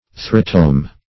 Search Result for " urethrotome" : The Collaborative International Dictionary of English v.0.48: Urethrotome \U*re"thro*tome\, n. [Urethra + Gr.